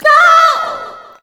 SCREAM 9  -L.wav